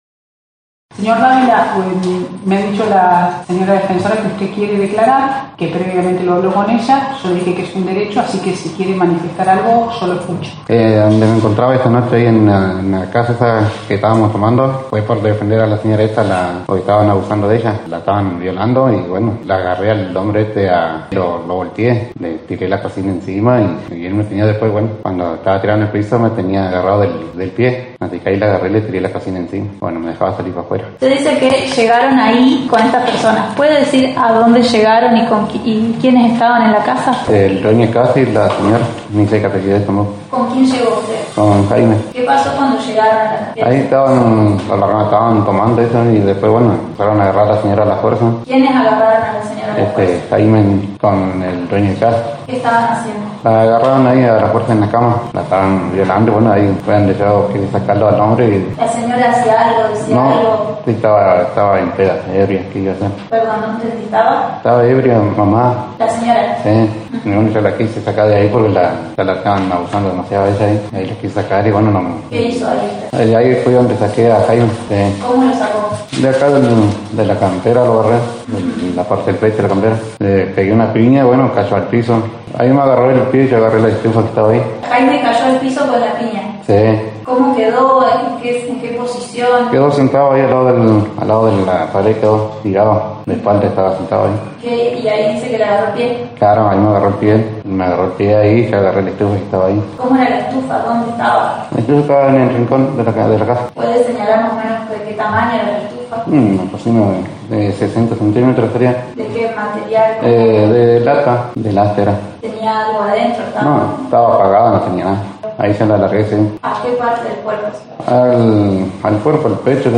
Noticias de Esquel fue el único medio presente en la audiencia, te ofrece el audio del momento de la acusación y luego la declaración del detenido.
Luego el detenido dio su versión de los hechos.